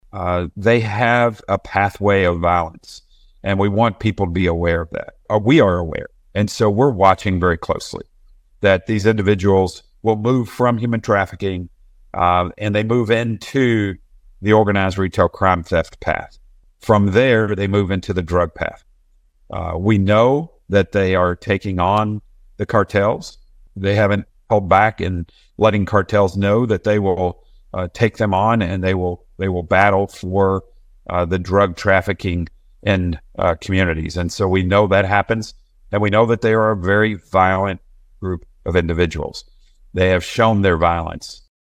Director Rausch said the gang members are dangerous.(AUDIO)